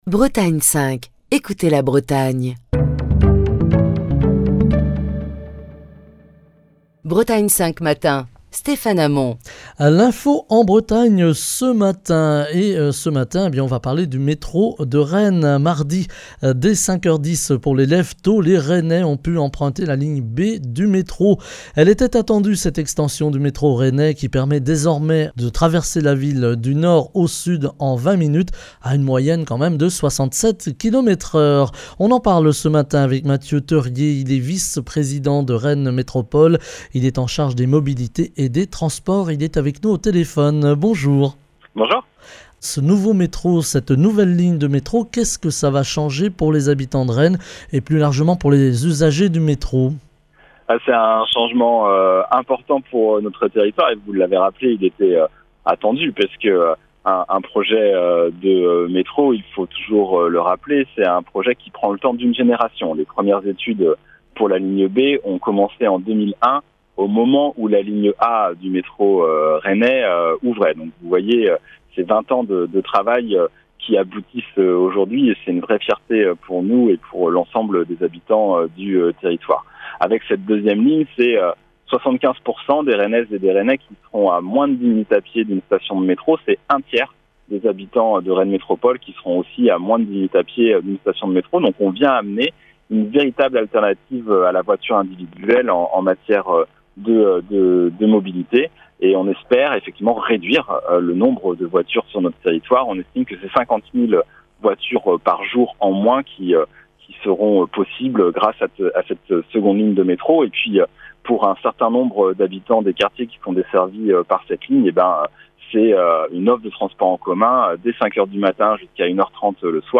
Mardi, dès 5h10 pour les lèves-tôt, les rennais ont pu emprunter la ligne B du métro. Elle était attendue cette extension du métro rennais, qui permet désormais de traverser la ville du Nord au Sud en 20 minutes à une moyenne de 67 km/h. Matthieu Theurier, Vice-président de Rennes Métropole en charge des Mobilités et des Transports est notre invité ce matin.